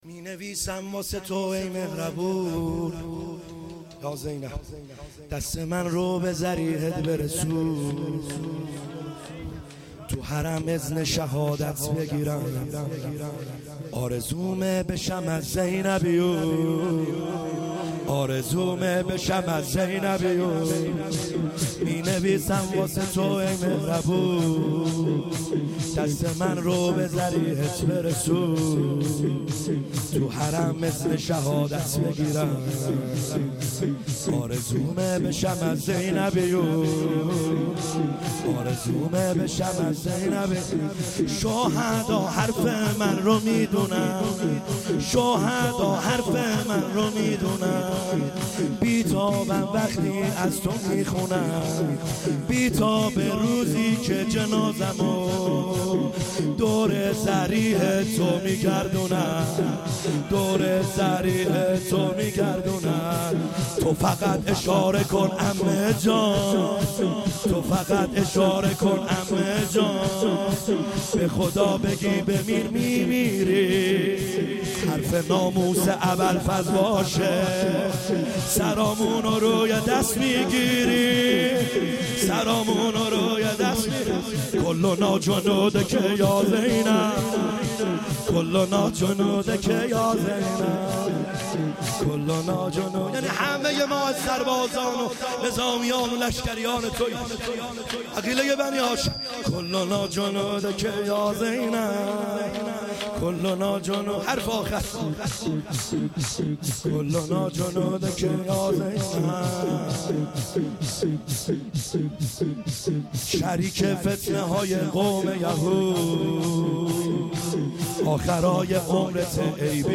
خیمه گاه - بیرق معظم محبین حضرت صاحب الزمان(عج) - شور | مینویسم واسه تو ای مهربون